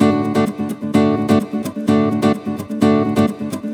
VEH2 Nylon Guitar Kit 128BPM
VEH2 Nylon Guitar Kit - 11 D maj.wav